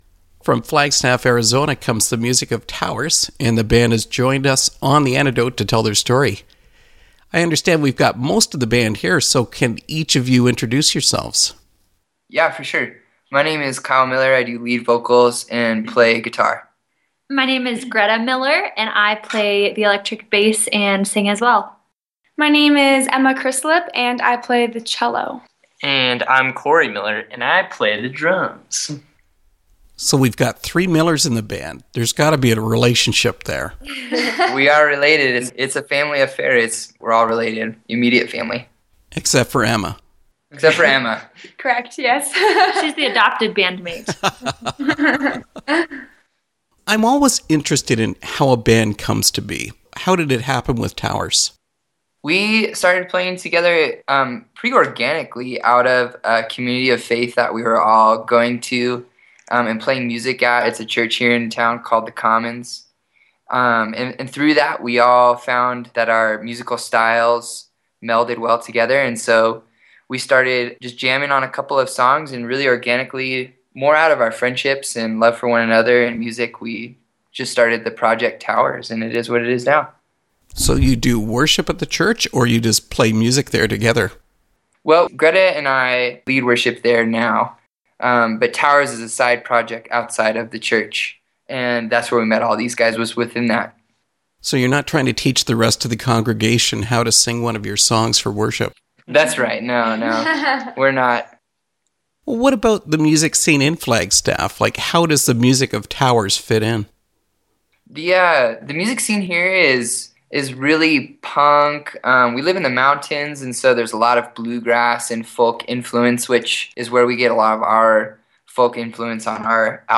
Interview with Tow’rs
tow'rs-interview.mp3